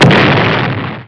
Explosion1.wav